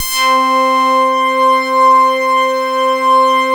BAND PASS .6.wav